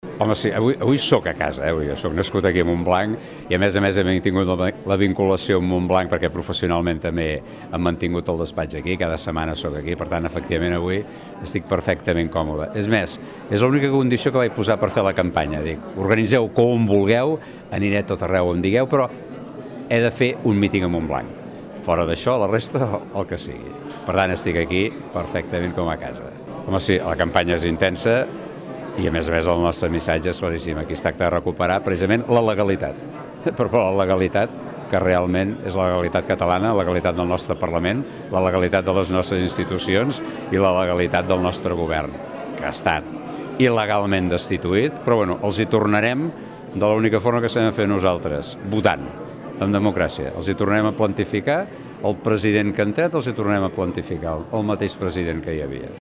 Junts per Catalunya va celebrar aquest dimecres a Montblanc el seu acte central de campanya a la Conca de Barberà. Unes 130 persones van omplir la sala de les Corts Catalanes del claustre de Sant Francesc per escoltar els arguments de la formació de Puigdemont.